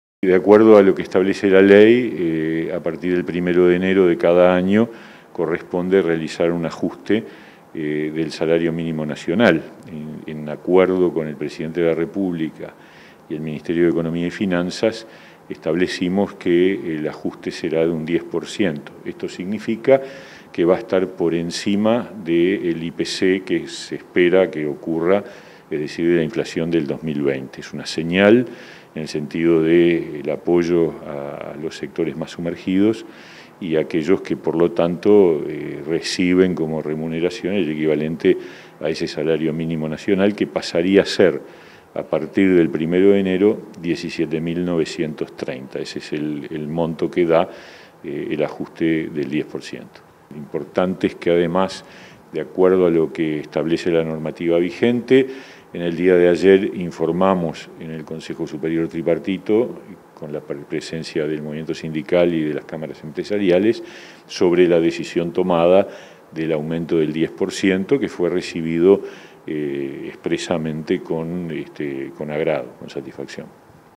En diálogo con Comunicación Presidencial, el ministro de Trabajo y Seguridad Social recordó que el ajuste del salario mínimo nacional está establecido en la ley nº 10.449 para el 1º de enero de cada año.